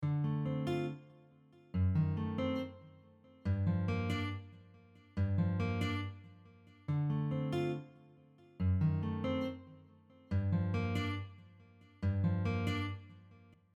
Here, the F# dim7 is acting as a 5 chord (D7 b9) of the G minor 7 chord.
Diminished-chord-progressions-on-guitar-2.mp3